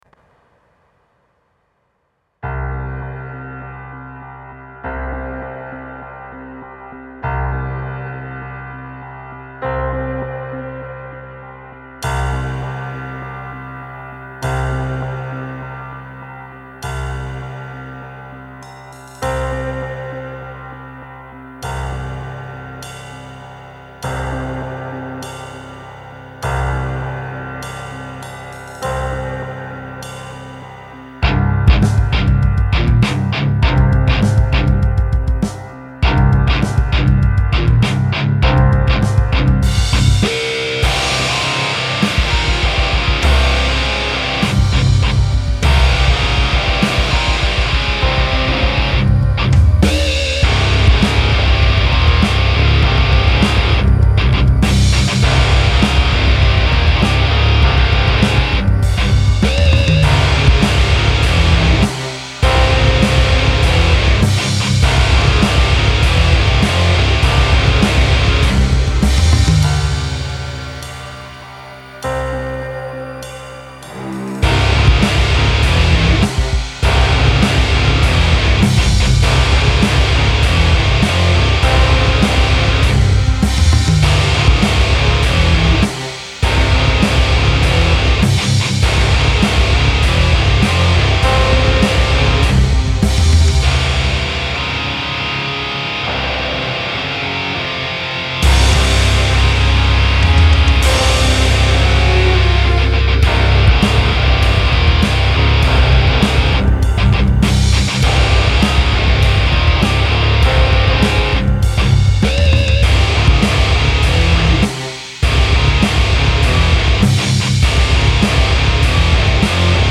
Аранжировка и сведение металла